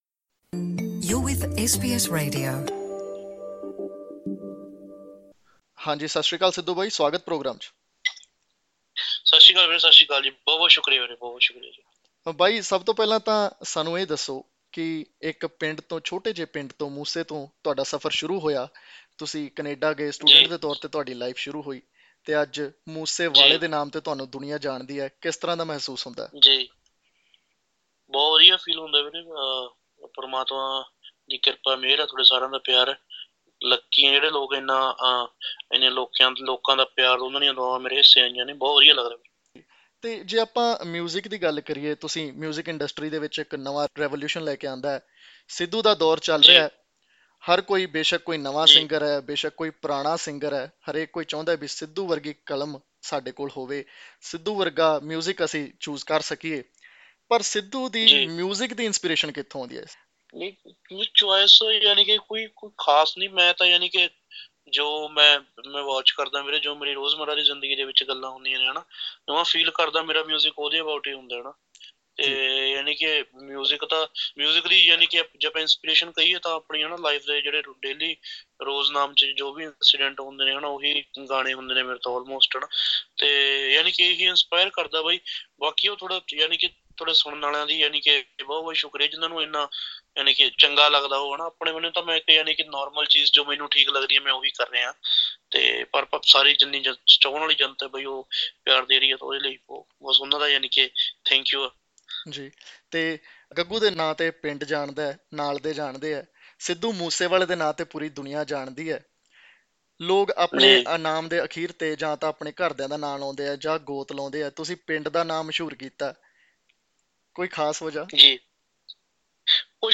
In an exclusive interview with SBS Punjabi, the singer-turned-actor Sidhu Moosewala talks about his journey from an obscure village called Moosa in Punjab's Mansa district to revolutionising Punjabi music that has over the years struck a chord with listeners around the world.